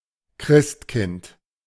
The Christkind (German for 'Christ-child'; pronounced [ˈkʁɪstˌkɪnt]
De-Christkind.ogg.mp3